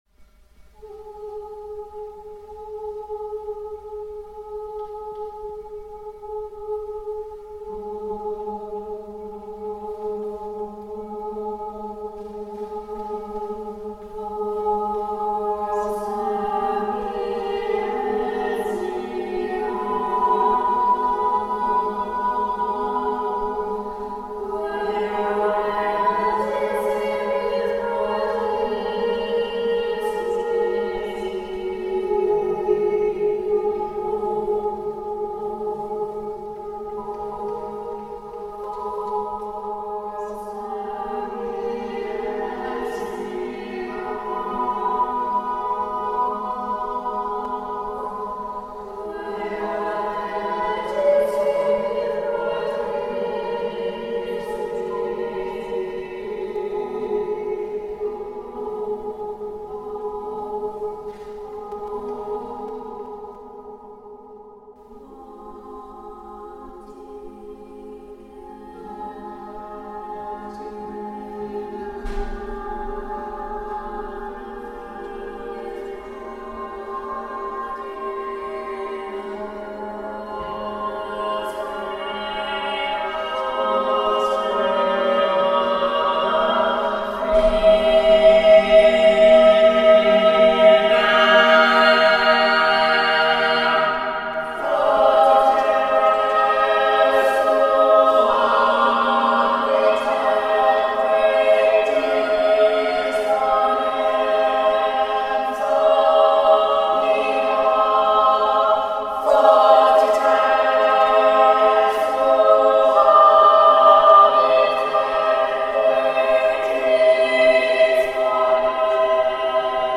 Accompaniment:      Reduction
Music Category:      Choral